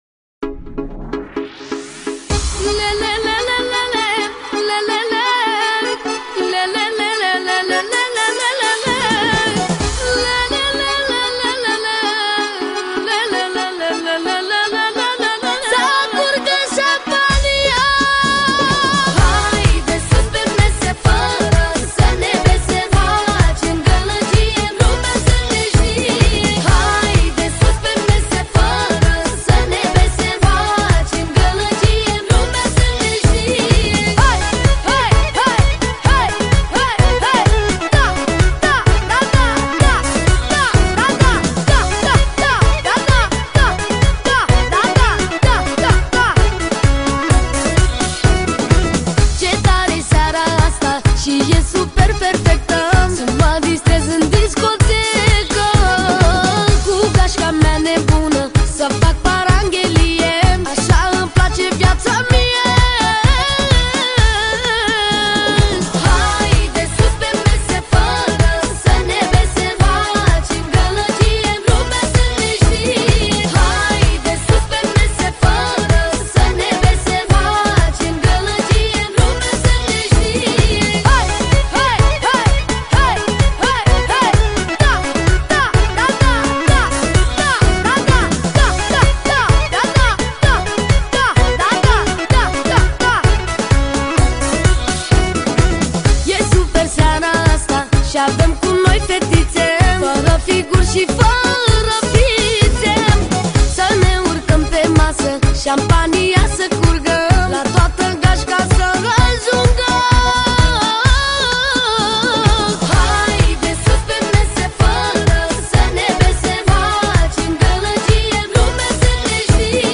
Manele Vechi